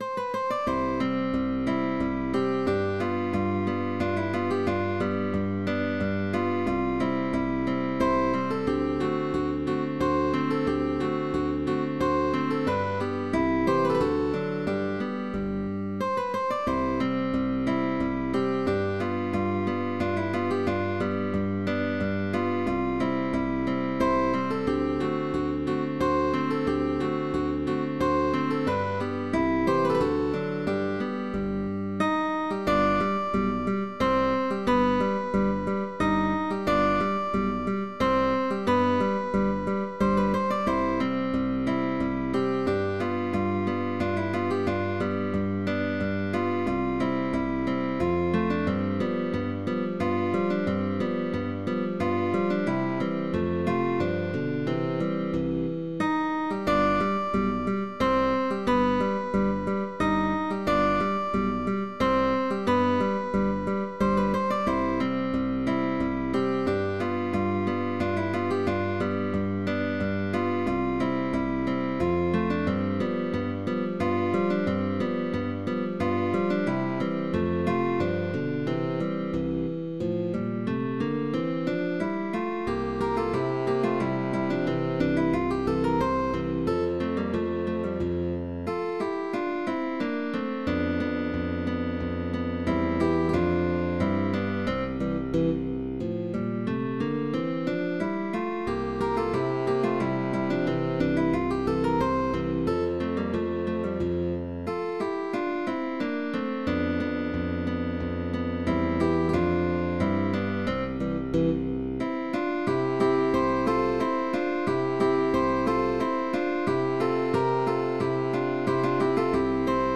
GUITAR TRIO
Classicism